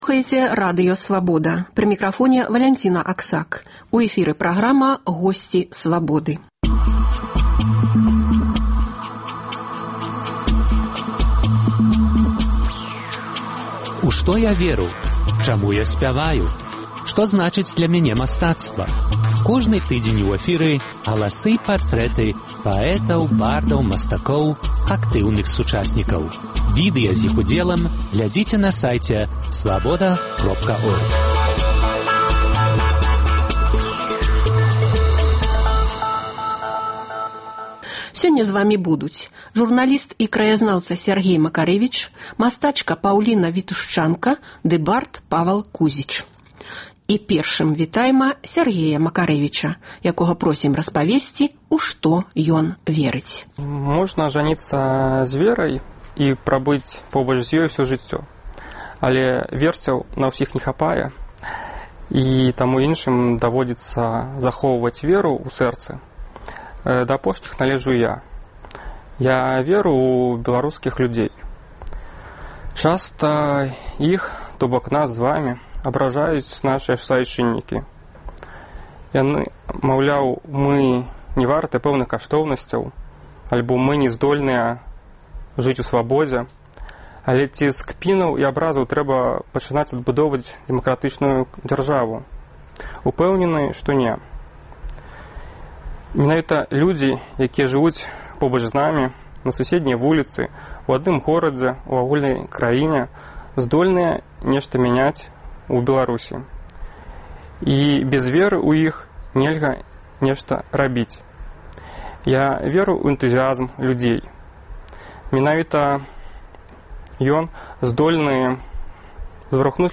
Галасы і партрэты паэтаў, бардаў, мастакоў, актыўных сучасьнікаў. У студыі